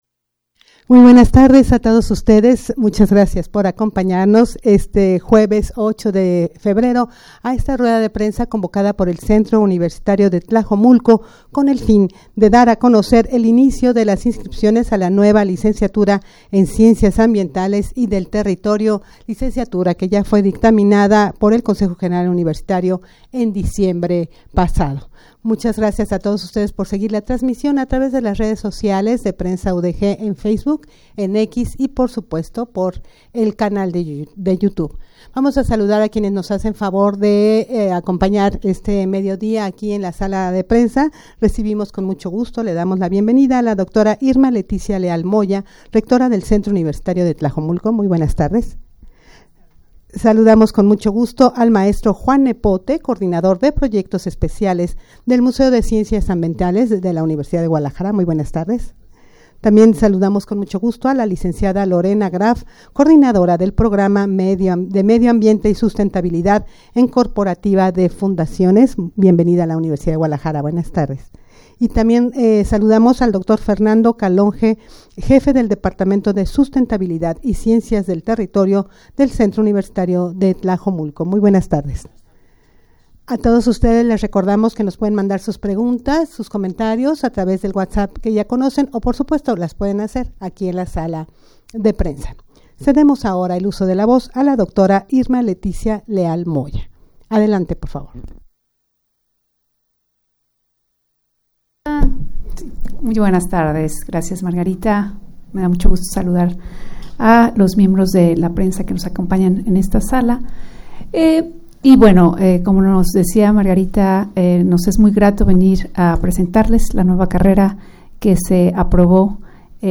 Audio de la Rueda de Prensa